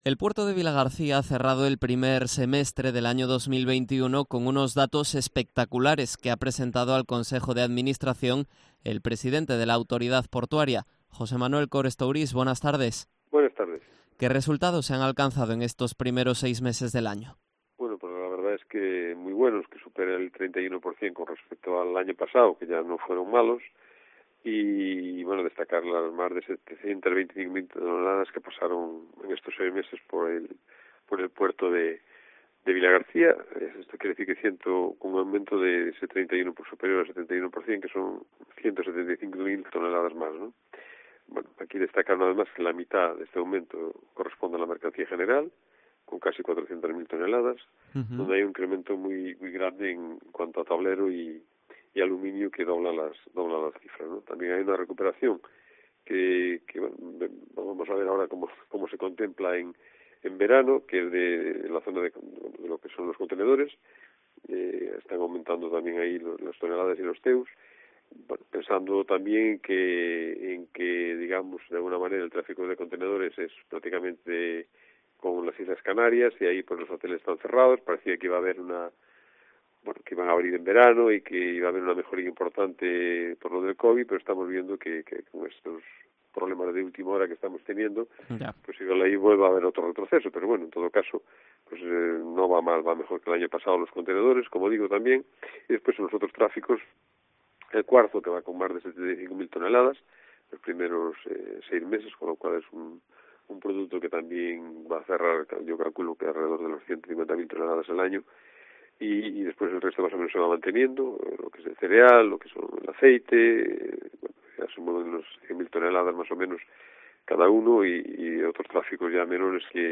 Entrevista a José Manuel Cores Tourís, presidente de la Autoridad Portuaria de Vilagarcía